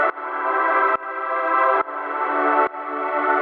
GnS_Pad-dbx1:2_140-E.wav